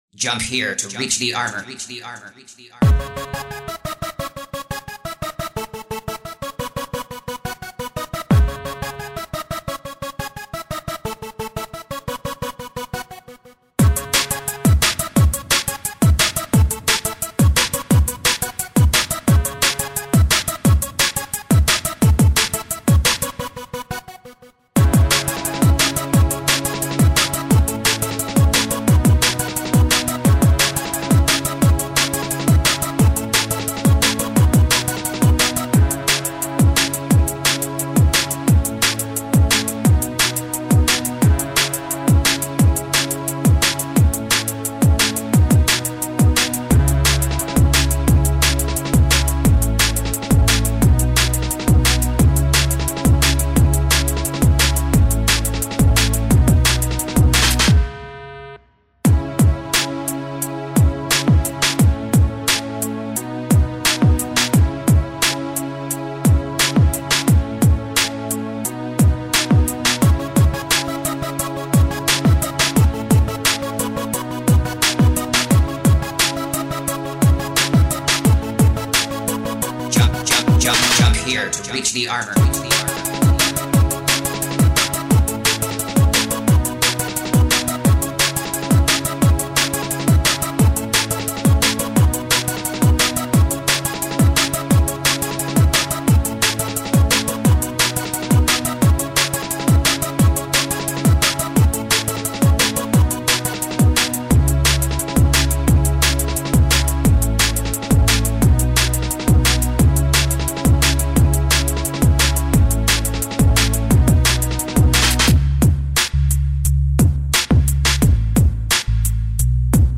mega_bass_prikolnye_bassy.mp3